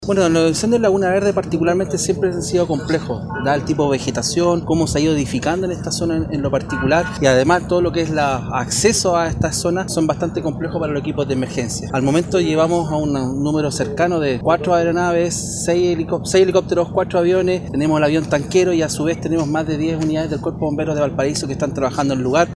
En tanto, el director regional subrogante de Senapred, Patricio Araneda, detalló que en la emergencia han trabajado el Aero Tanker, 15 brigadas, cinco aviones, cinco helicópteros y Bomberos.